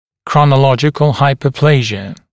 [ˌkrɔnə’lɔʤɪkl ˌhaɪpə(u)’pleɪʒɪə][ˌкронэ’лоджиклˌхайпо(у)’плэйжиэ]хронологическая гипоплазия